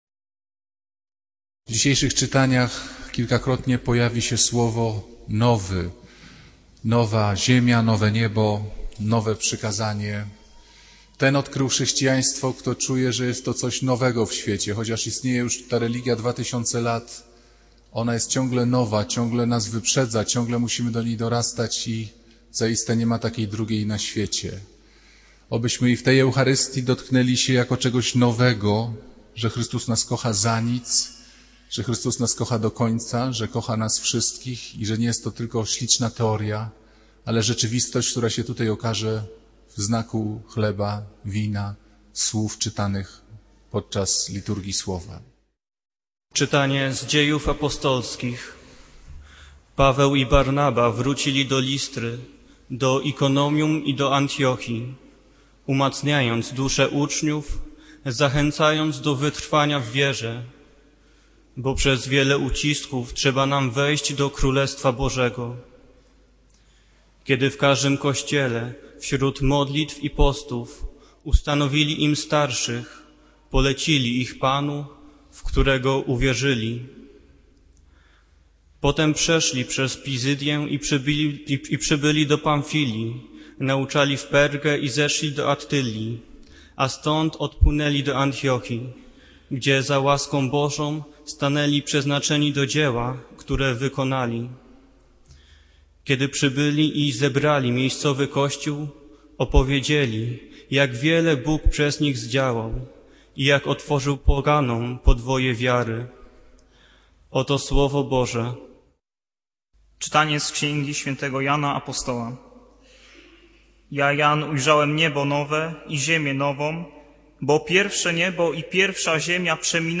Kazania księdza Pawlukiewicza o nowym przykazaniu miłości.
W nagraniu kapłan ukazuje, jak radykalnie różni się chrześcijańska miłość od zwykłej sympatii czy obowiązku – to miłość bezwarunkowa, także wobec nieprzyjaciół. Kazanie porusza temat nowości Ewangelii i jej przesłania, które nawet po dwóch tysiącach lat nadal wyprzedza naszą codzienność.